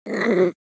Minecraft Version Minecraft Version latest Latest Release | Latest Snapshot latest / assets / minecraft / sounds / mob / wolf / cute / growl1.ogg Compare With Compare With Latest Release | Latest Snapshot
growl1.ogg